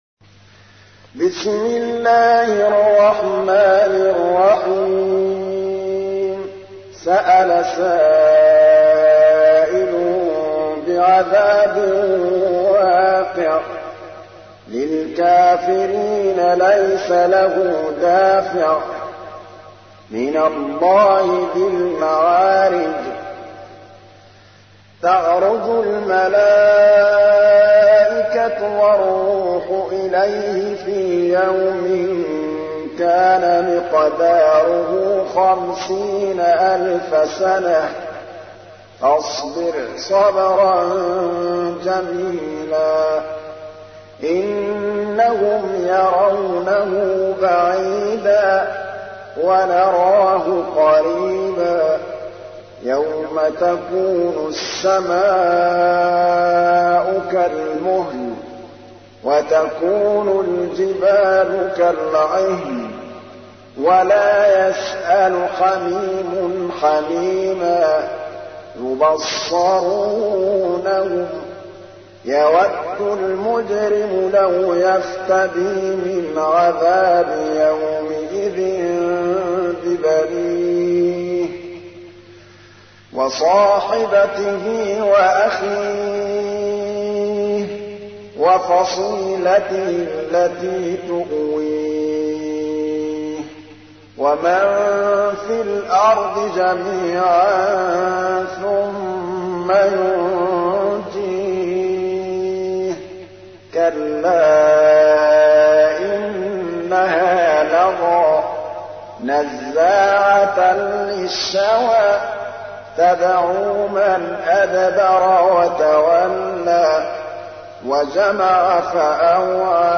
تحميل : 70. سورة المعارج / القارئ محمود الطبلاوي / القرآن الكريم / موقع يا حسين